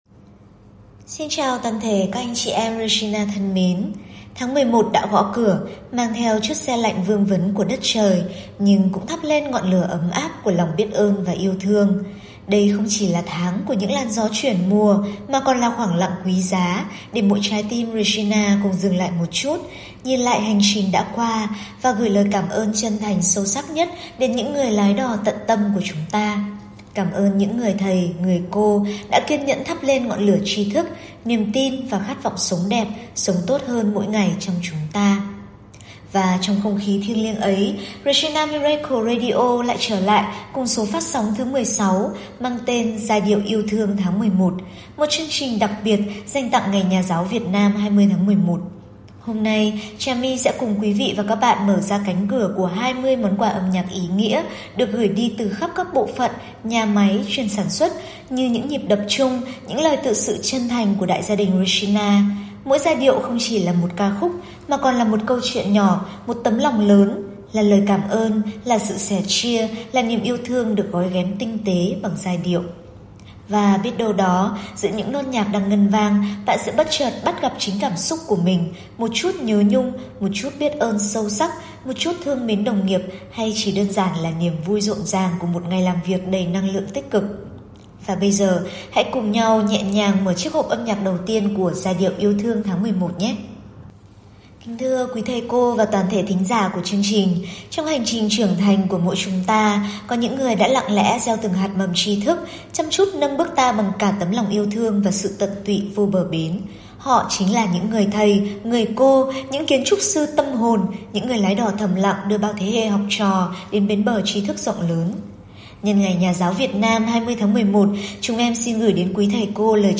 Chúng ta cùng nhau bày tỏ lòng biết ơn sâu sắc đến những người thầy, người cô – những “người lái đò” thầm lặng đã thắp lên ngọn lửa tri thức và niềm tin trong ta. 20 món quà âm nhạc, từ khắp các nhà máy, đã trở thành những “nhịp tim chung” lan tỏa khắp Regina.